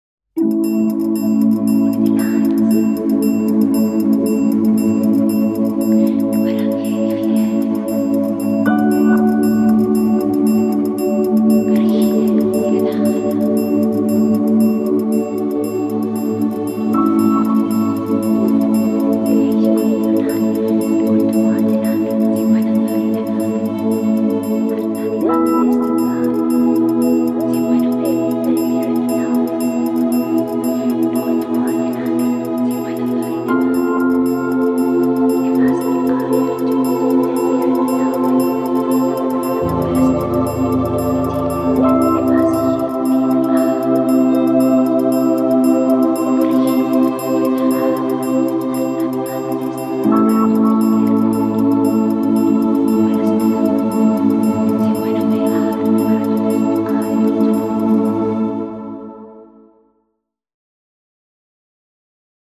epique - percussions - profondeurs - voix - ciel